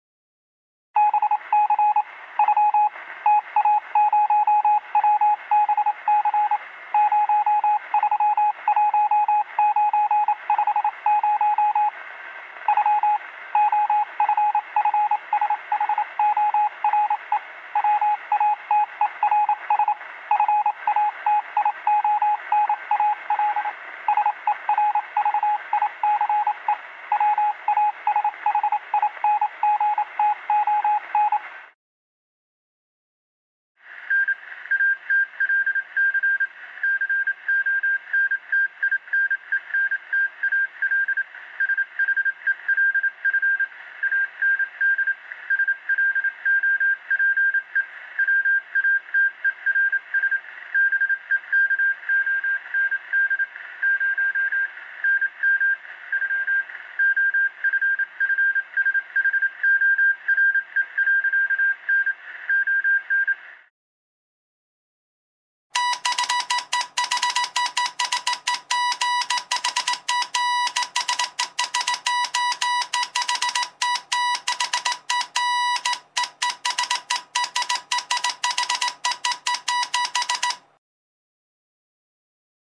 Aba Signals In Morse Code Mp 3